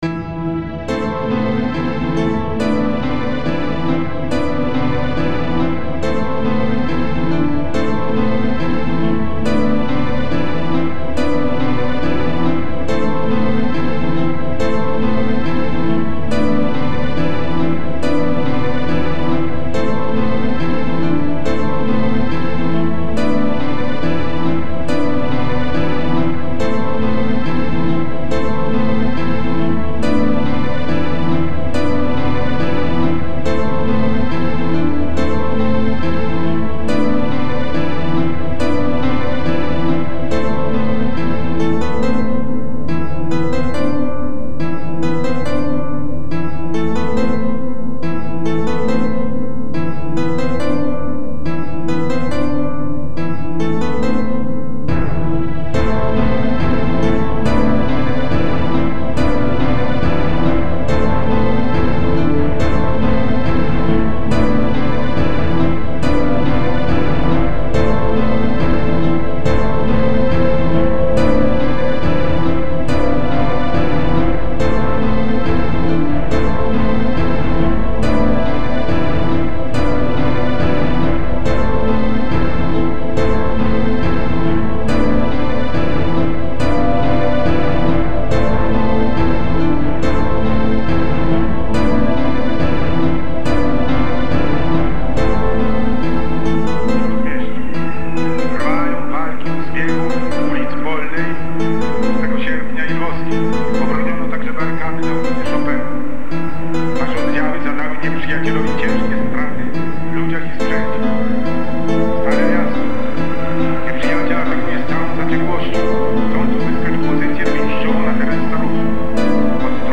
インダストリアル色は薄く、どちらかというとアンビエント、ダーク・アンビエント、エクスペリメンタル的色合いが強いのが特徴。